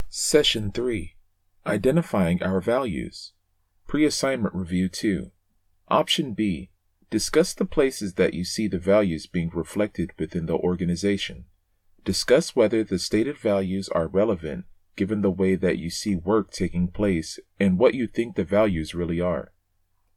Voice Over Work